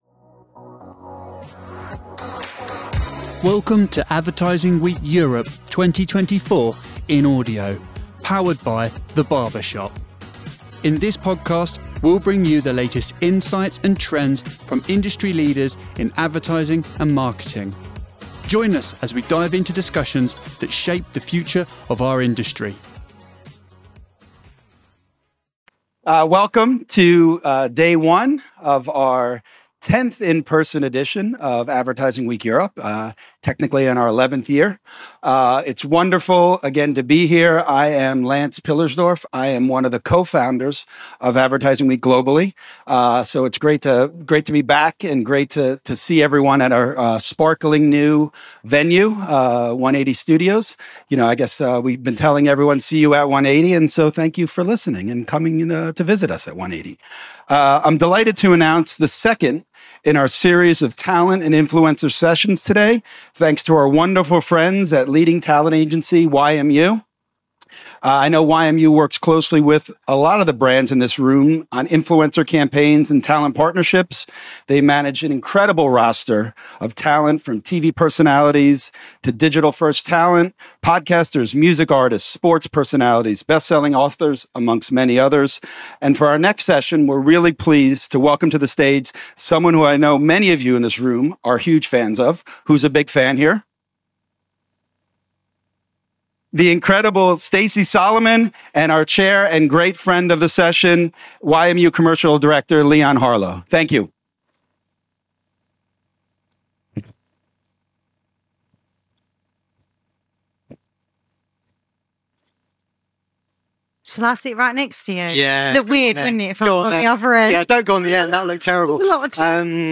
In Conversation with Stacey Solomon: Creating High-Impact Partnerships & Supporting Female-Backed Businesses